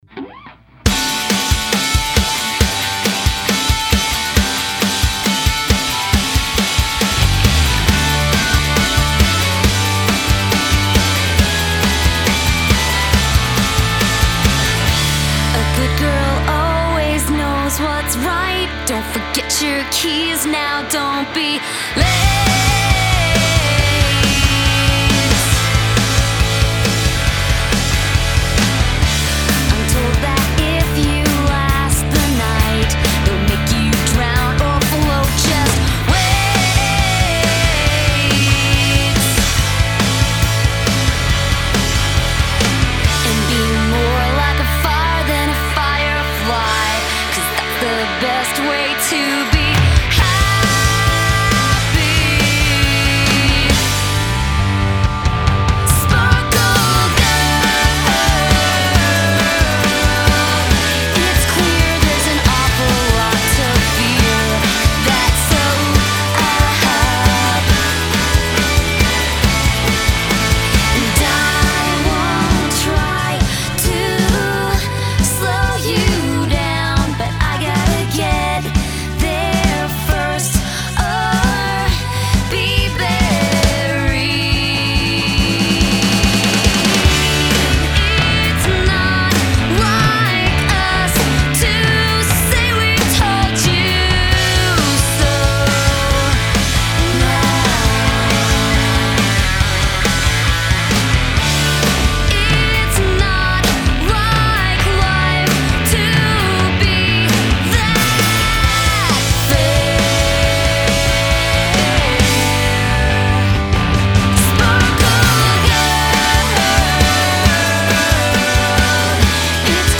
alt-pop-rock